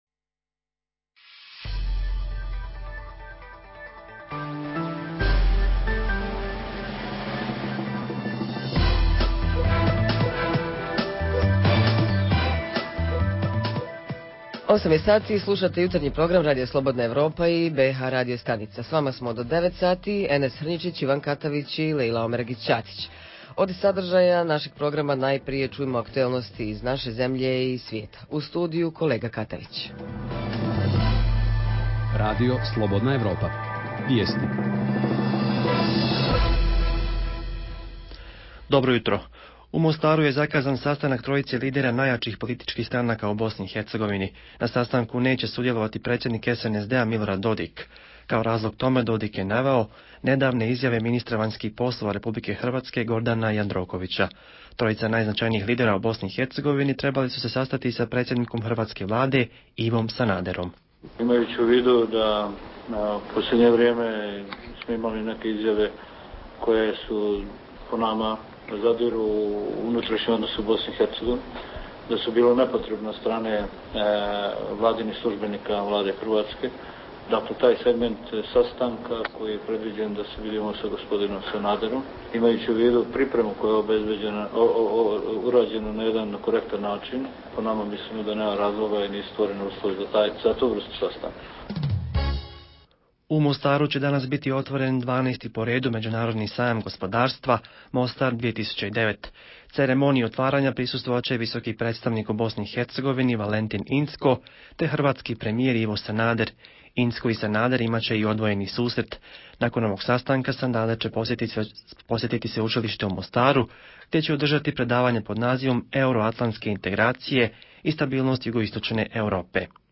Jutarnji program za BiH koji se emituje uživo. U zajedničkoj temi posvetićemo se malo načinima kako da sami sebi barem malo ublažimo ekonomsku krizu.
Redovni sadržaji jutarnjeg programa za BiH su i vijesti i muzika.